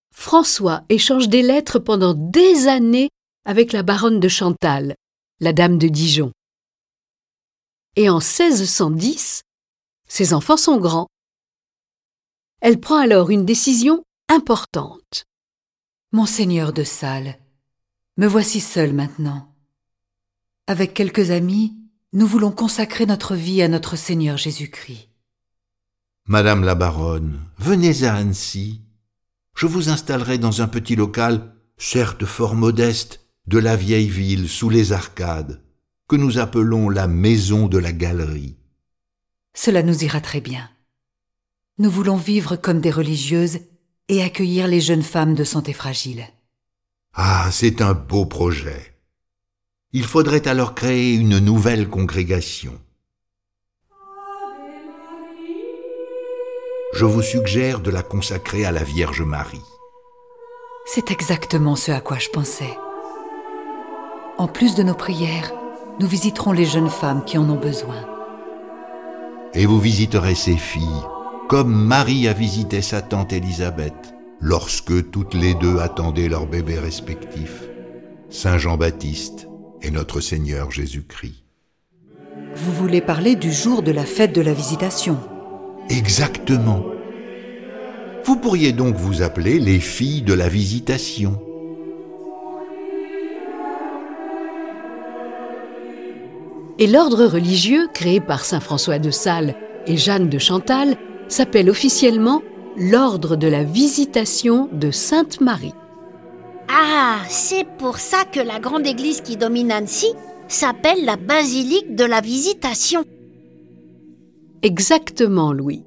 Cette version sonore de la vie de François de Sales est animée par 8 voix et accompagnée de plus de 30 morceaux de musique classique.